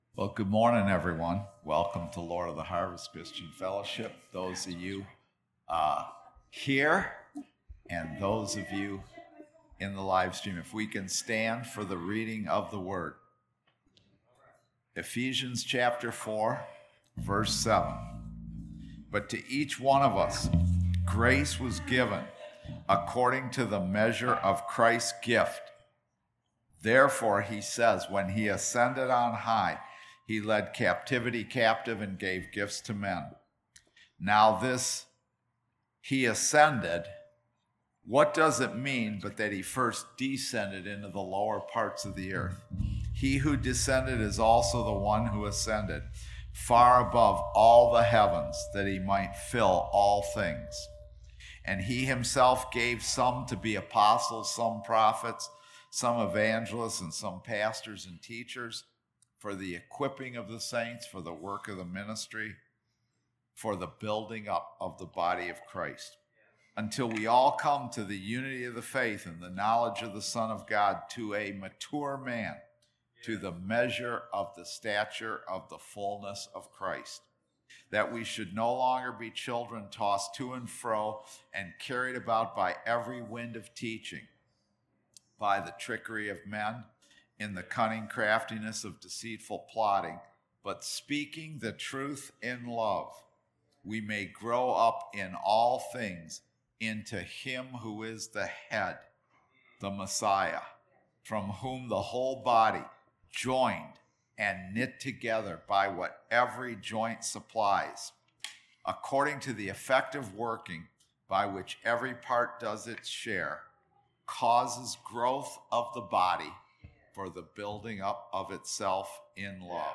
Sermon
Service Type: Sunday Service